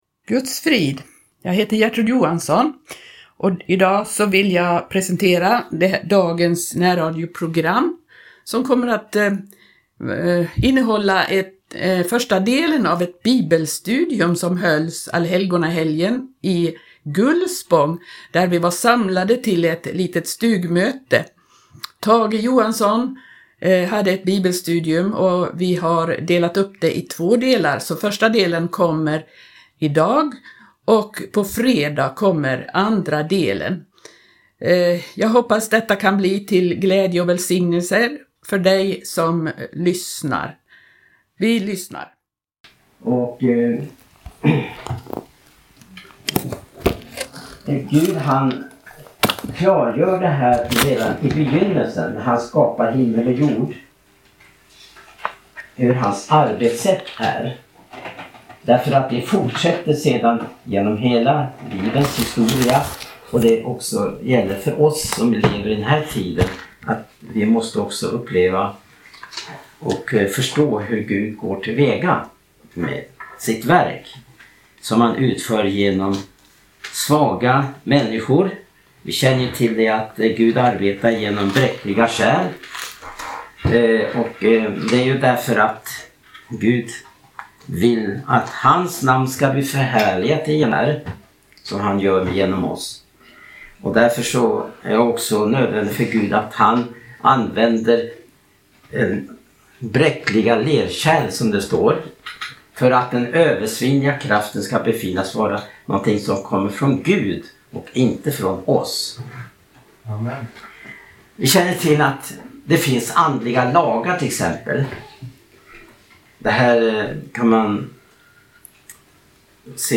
Stugmöte i Gullspång (del 1)